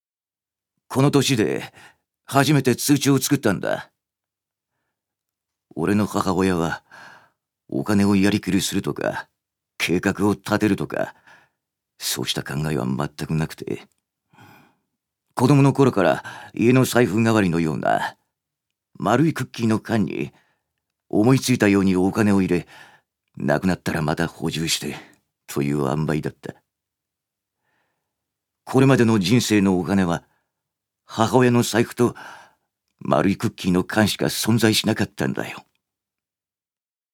預かり：男性
セリフ３